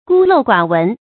注音：ㄍㄨ ㄌㄡˋ ㄍㄨㄚˇ ㄨㄣˊ
孤陋寡聞的讀法